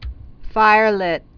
(fīrlĭt)